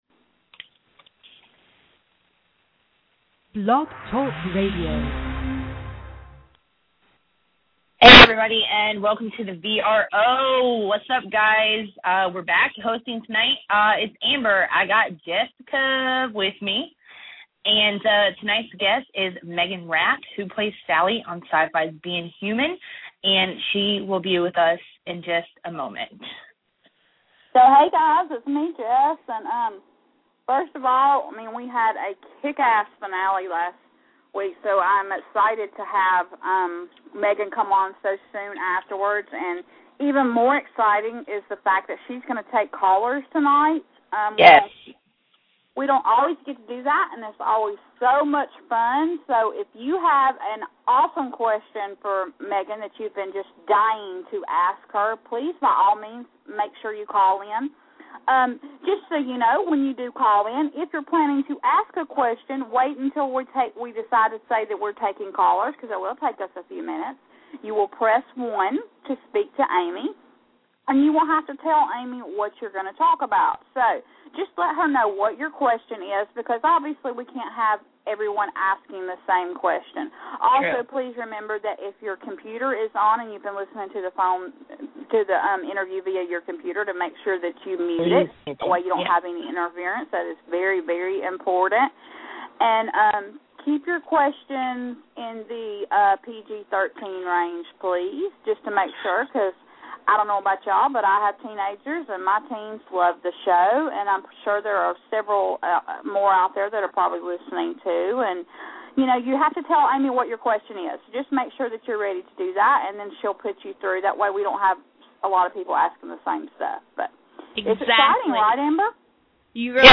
Meaghan Rath "Being Human" Interview